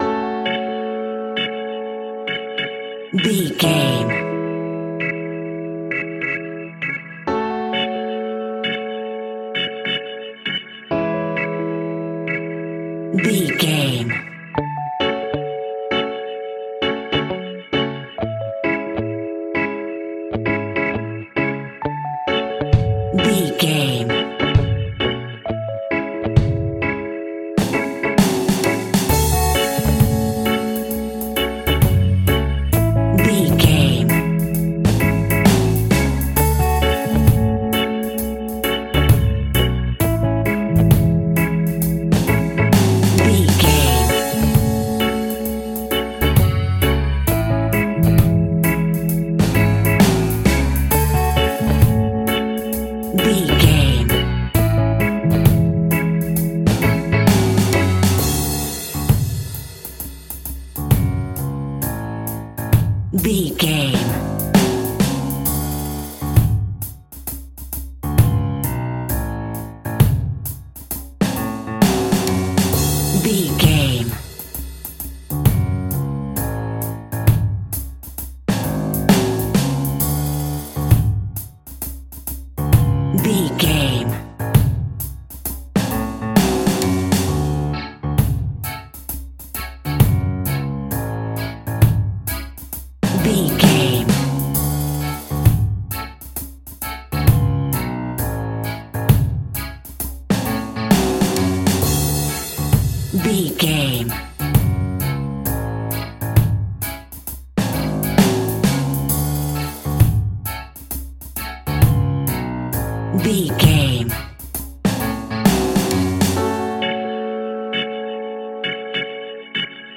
A chilled and relaxed piece of smooth reggae music!
Aeolian/Minor
F#
off beat
drums
skank guitar
hammond organ
percussion
horns